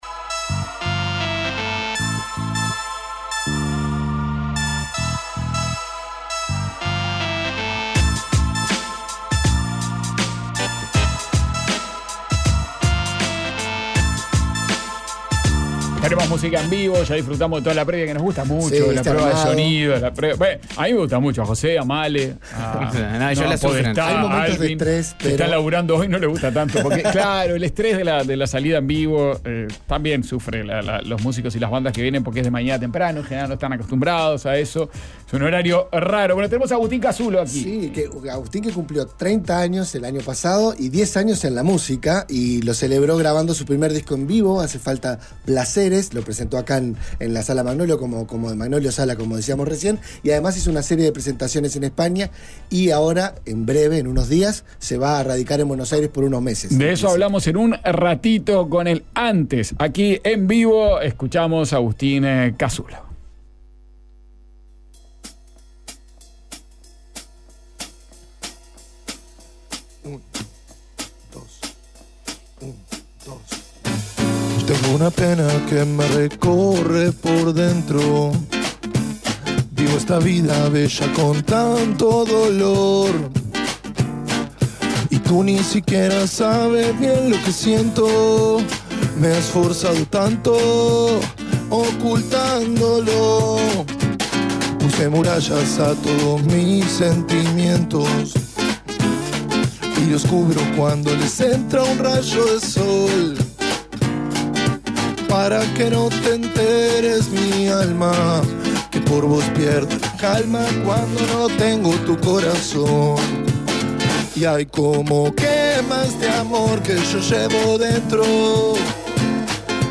tocaron dos temas en vivo